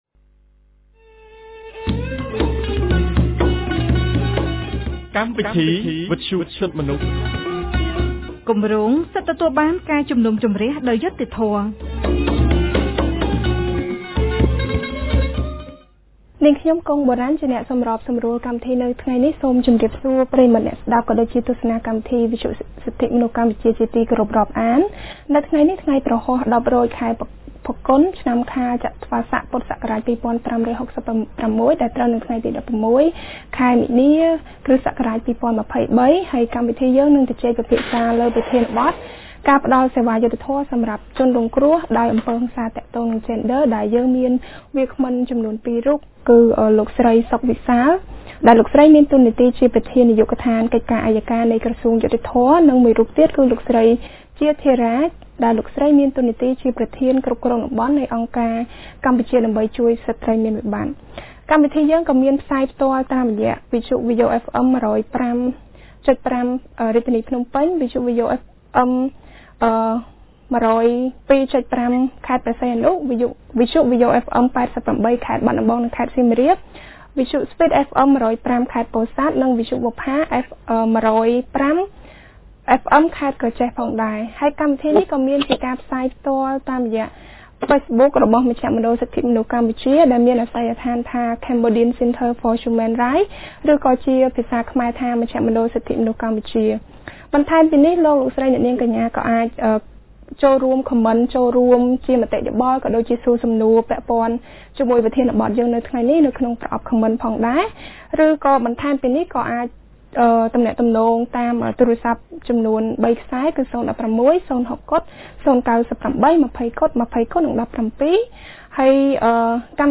គម្រោងសិទ្ធិទទួលបានការជំនុំជម្រះដោយយុត្តិធម៌ បានរៀបចំកម្មវិធីវិទ្យុក្រោមប្រធានបទស្តីពី ការផ្តល់សេវាយុត្តិធម៌សម្រាប់ជនរងគ្រោះដោយអំពើហិង្សាទាក់ទងនឹងយេនឌ័រ